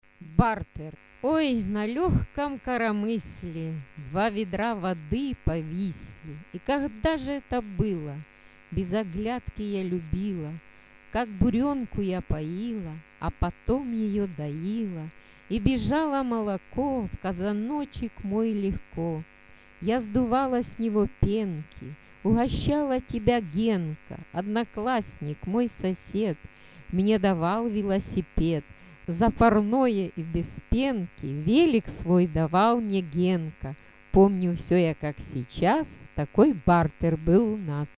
Начитан автором